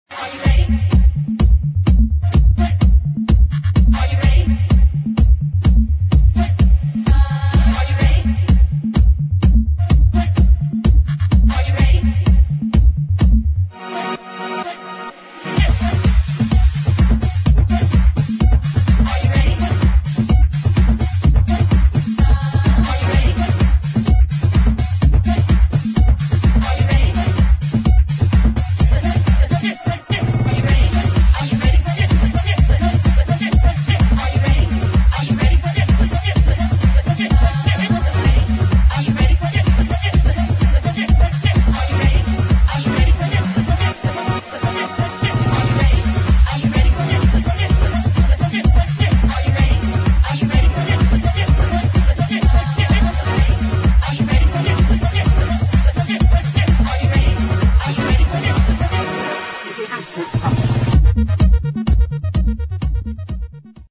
[ HOUSE / TECH HOUSE ]